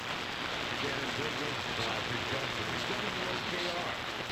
I received WOKR 1310 right now but a very weak signal as you can hear on the sample below. I am using a terk tunable loop antenna and my Sony FM/AM stereo receiver.